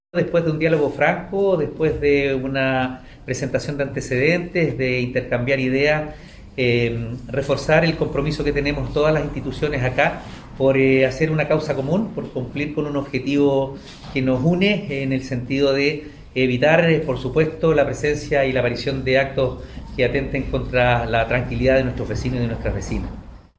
05-ALCALDE-OSCAR-CALDERON-Reforzar-compromisos.mp3